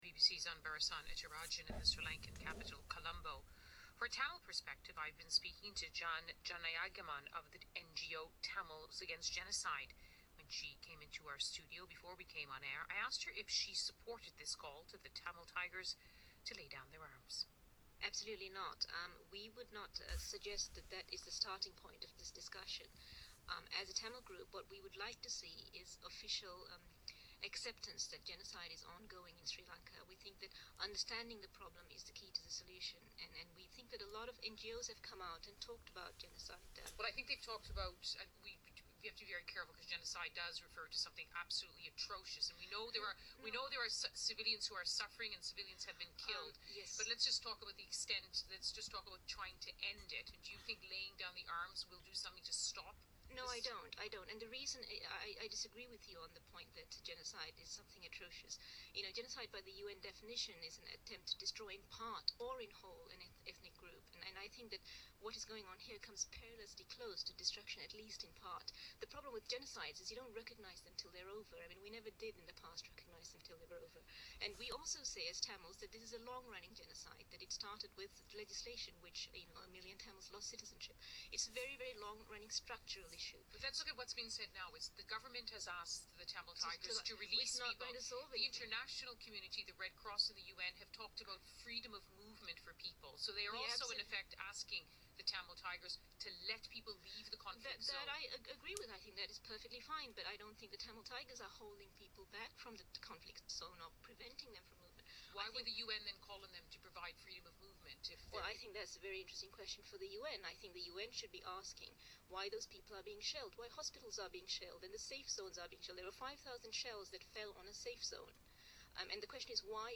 Tamils Against Genocide BBC Interview
BBC Interview MP3 - 5.7MB